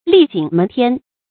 歷井捫天 注音： ㄌㄧˋ ㄐㄧㄥˇ ㄇㄣˊ ㄊㄧㄢ 讀音讀法： 意思解釋： 井：星宿名。手能觸天，形容極高 出處典故： 宋 蘇轍《卜居賦》：「諸子送我， 歷井捫天 ，汝不忘我，我不忘先。」